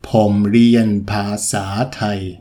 ∨ pomm – riian – paa ∨ saa – thai